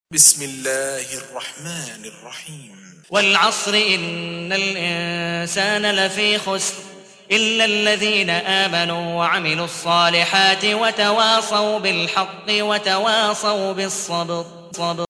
103. سورة العصر / القارئ